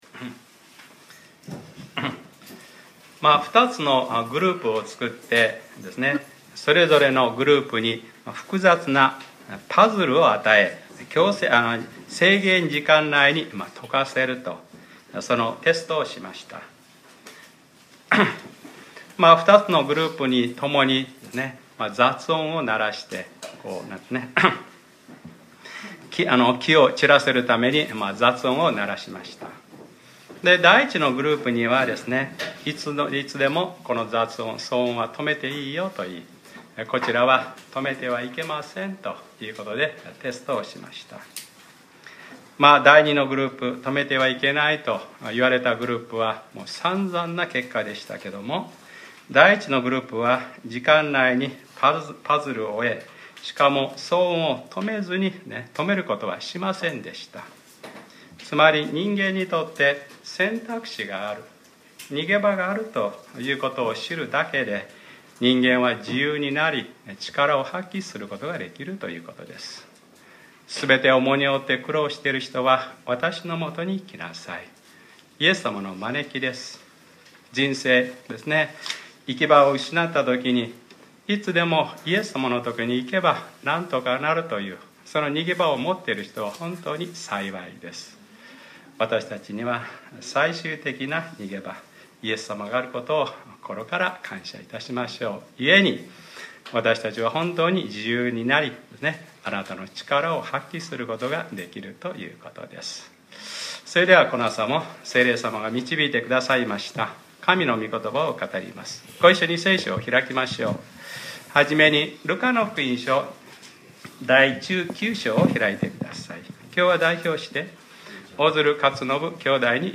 2015年09月13日（日）礼拝説教 『ルカｰ６８：ああ、エルサレム、エルサレム。』